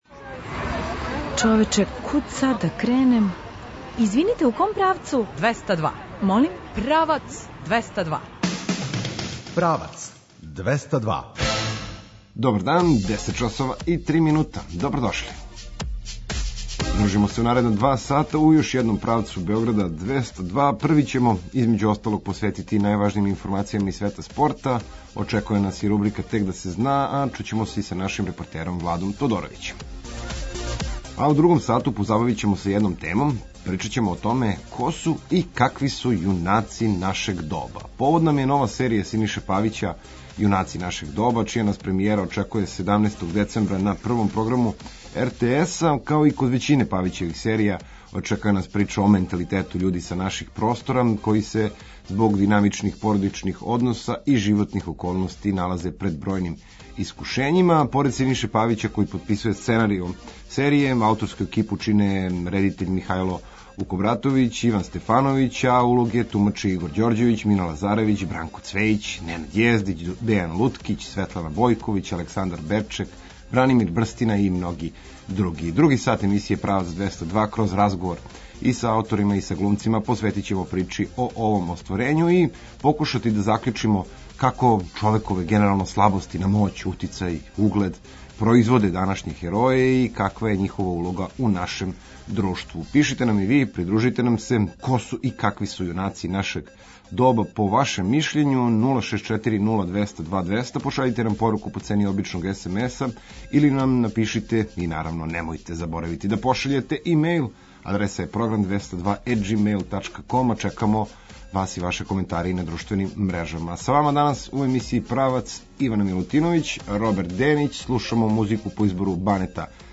Други сат емисије „Правац 202”, кроз разговор са ауторима и глумцима, посветићемо причи о овом остварењу и покушати да закључимо како човекове слабости на моћ, утицај и углед производе данашње „хероје” и каква је њихова улога у нашем друштву.